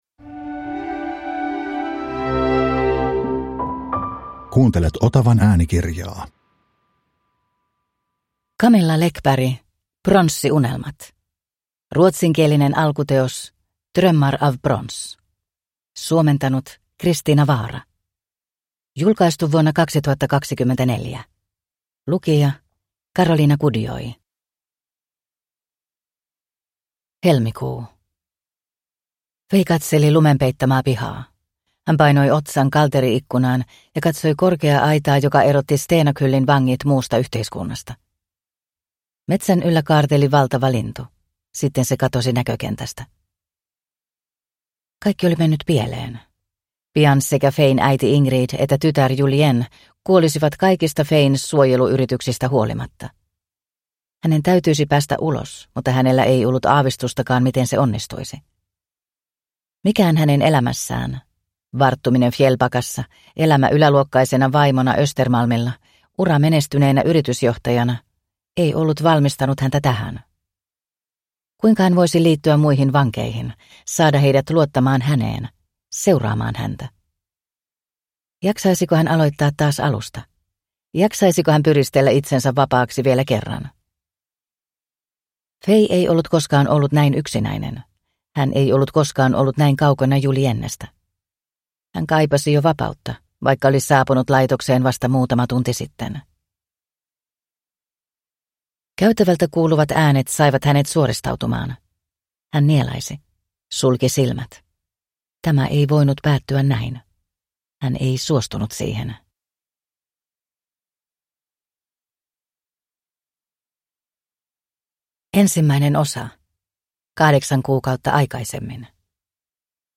Pronssiunelmat (ljudbok) av Camilla Läckberg | Bokon